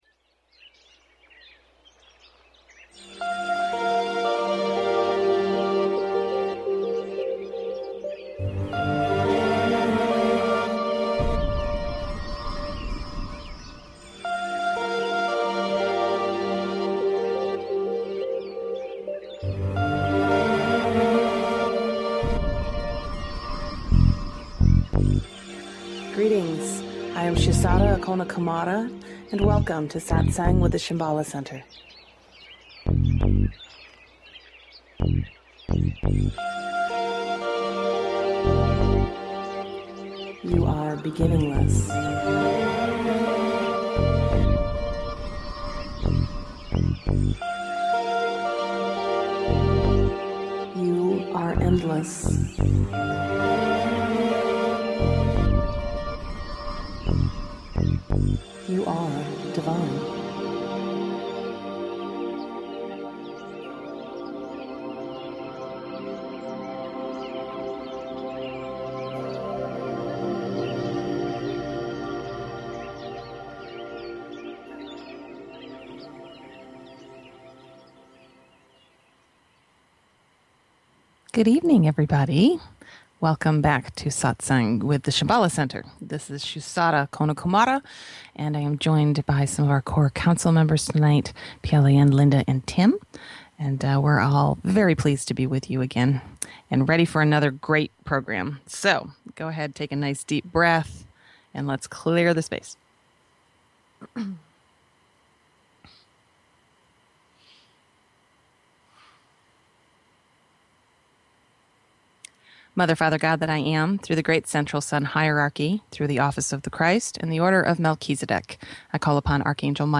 Talk Show Episode
The guide facilitates the session by holding the energy for the group, providing teachings, answering questions and facilitating meditations.